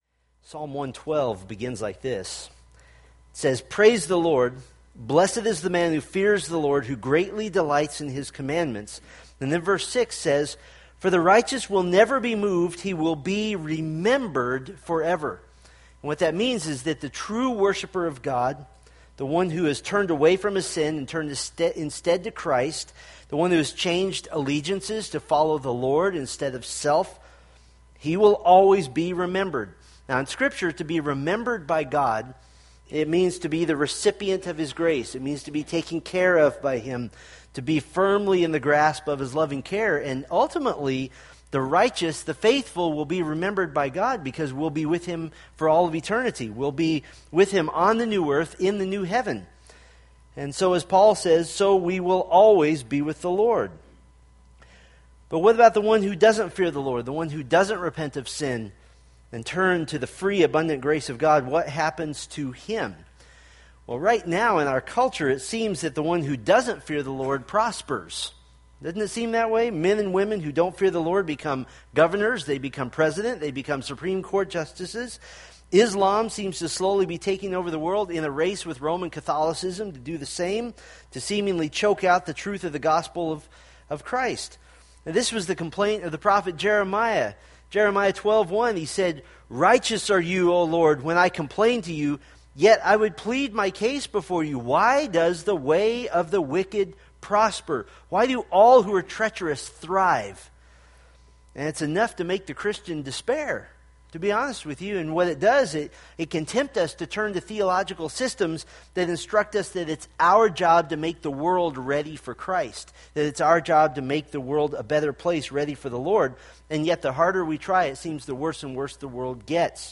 Homepage of Steadfast in the Faith, anchoring the soul in the Word of God by providing verse-by-verse exposition of the Bible for practical daily living.